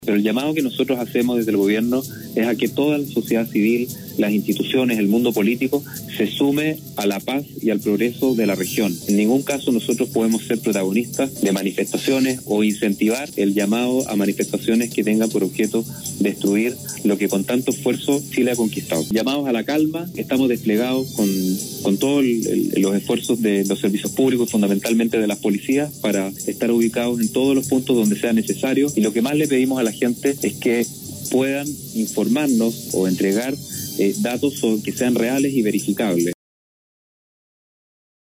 En exclusiva, el Intendente de Atacama, Patricio Urquieta, estableció un contacto telefónico con Nostálgica donde ofreció un balance general de lo que han sido las intensas jornadas de manifestaciones en el norte del país, recalcando que se encuentran realizando trabajos para restablecer todos los servicios públicos de la policía por lo que esperan retomar paulatinamente la normalidad de todas las actividades en la región de Atacama.